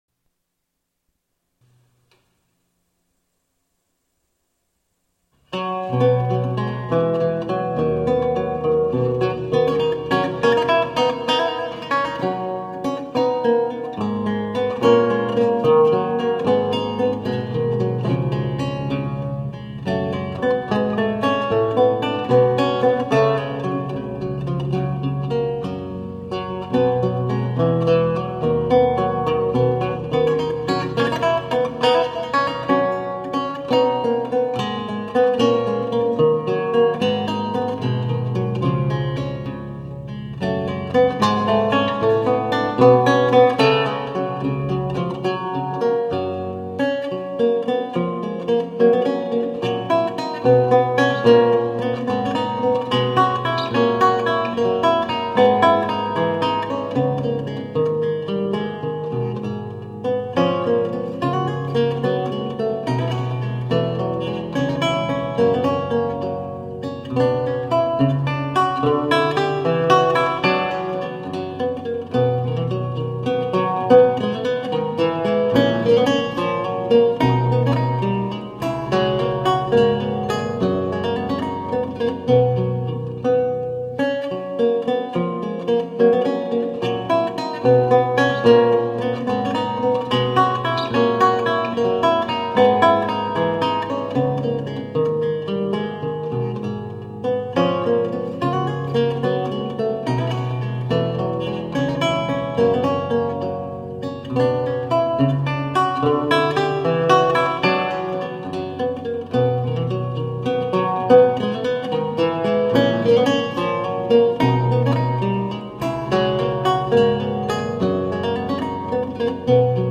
Classical, Baroque, Instrumental